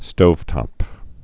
(stōvtŏp)